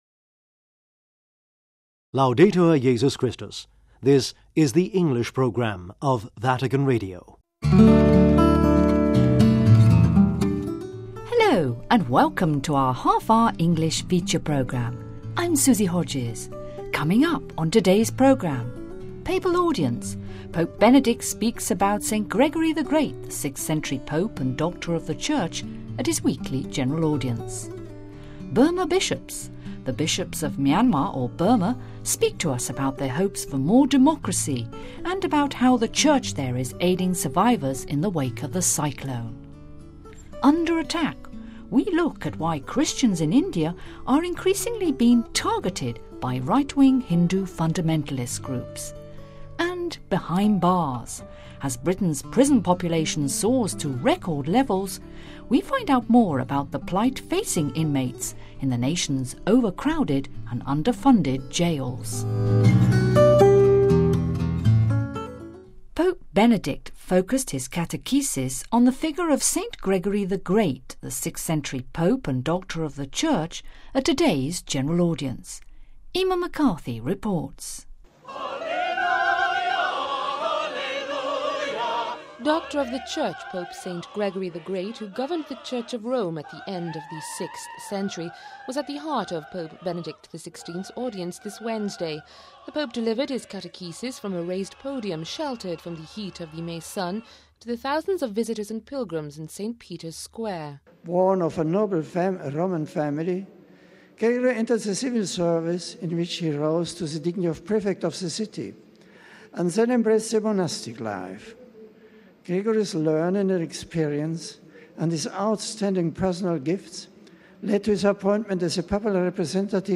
PAPAL AUDIENCE : Pope Benedict speaks about Saint Gregory the Great, the 6 th century pope and doctor of the church, at his general audience.